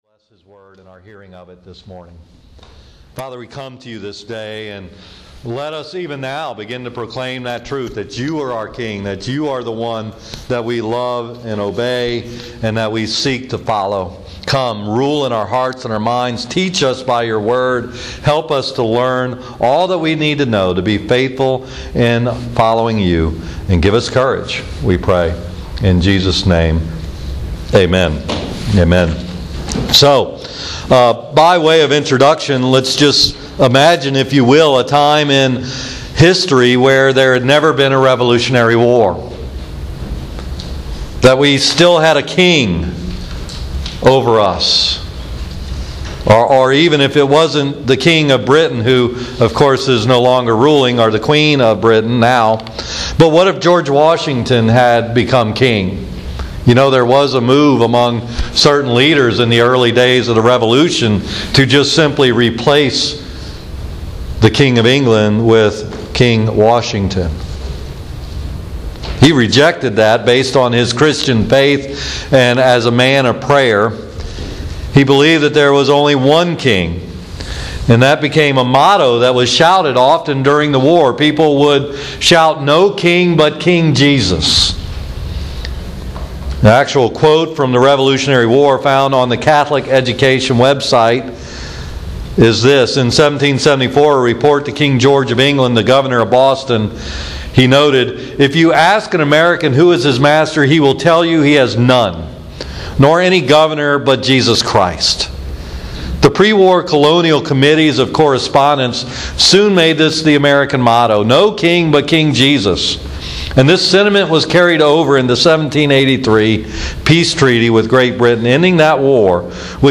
Again this series is part of a Church wide curriculum package so you may have actually heard similar sermons somewhere.